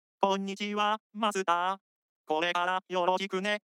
で、早速しゃべらせてみた。
ちこっとエフェクトかけてます。
といってハイパス通しただけですが。